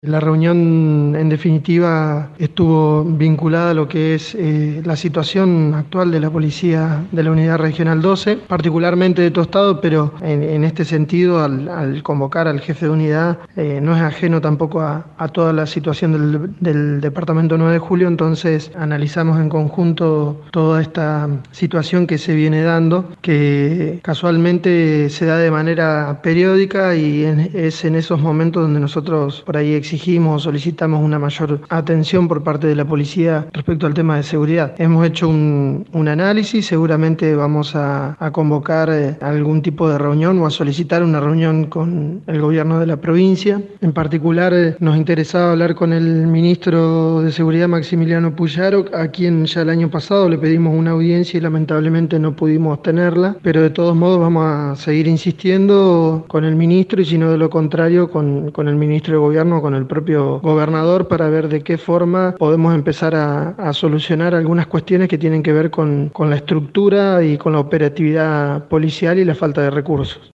Gerardo Bertolino, presidente del Concejo Deliberante de Tostado, brindó detalles de la reunión:
Gerardo-Bertolino-seguridad.mp3